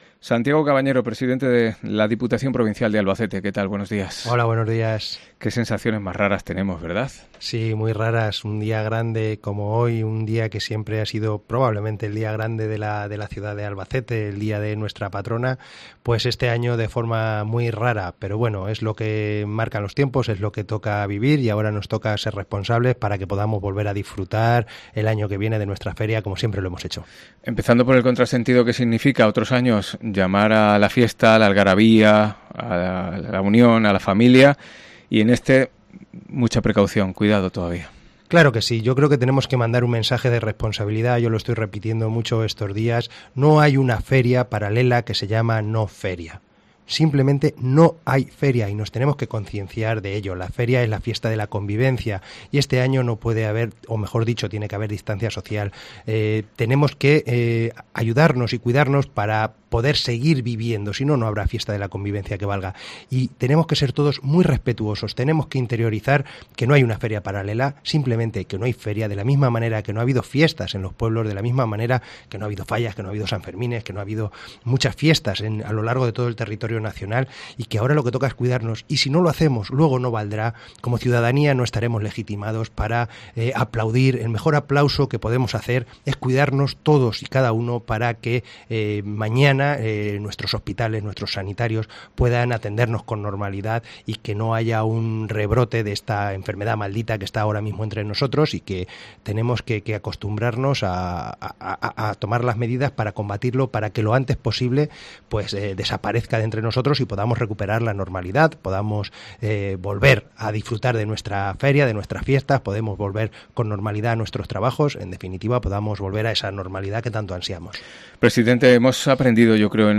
AUDIO: Santiago Cabañero, presidente de la Diputación Provincial, protagonista en COPE Albacete
ENTREVISTA